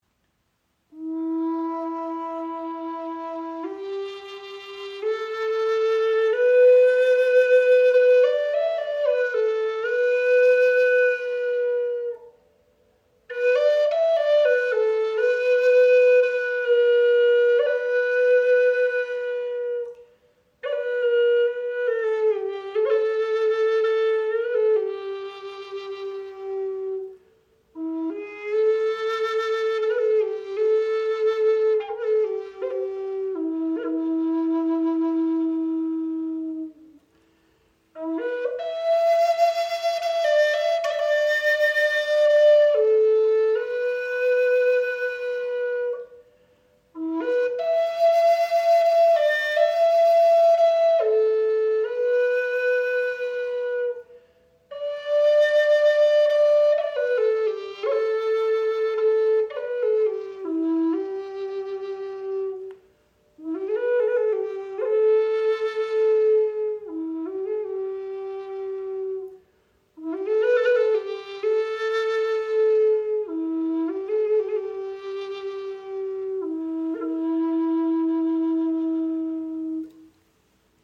• Icon Gesamtlänge 61 cm, 22 mm Innenbohrung – weich erdiger Klang
Gebetsflöte in E-Moll | Bären Windblock | Honduras-Palisander
Warmer, umarmender Klang für Meditation, Rituale und Klangreisen.
Gefertigt aus edlem Honduras-Palisander, einem geschätzten Tonholz mit hoher Klangstabilität, entfaltet sie einen weichen, tiefen und sanft umarmenden Ton, der ruhig trägt und lange nachschwingt – ideal für Meditation, Rituale und innere Klangreisen.
In E-Moll gestimmt, erklingt sie mit einem süssen und tiefen Klang, der weich, erdig und zugleich klar wirkt.